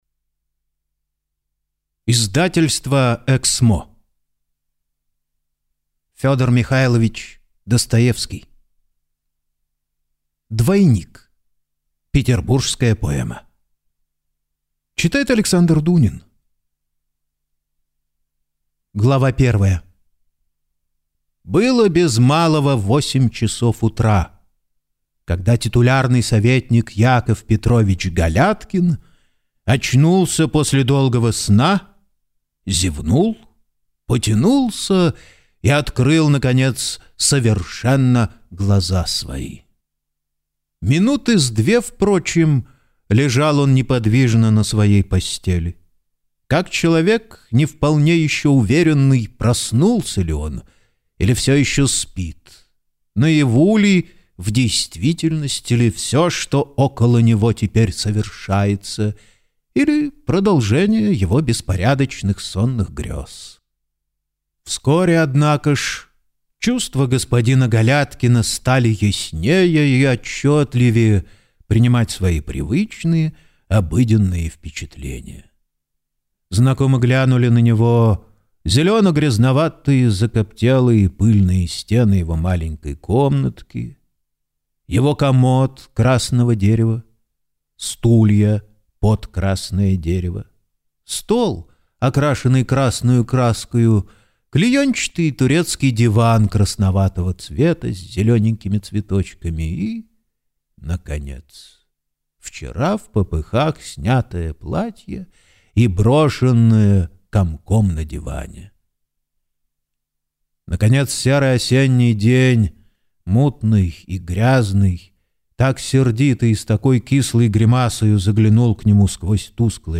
Аудиокнига Двойник | Библиотека аудиокниг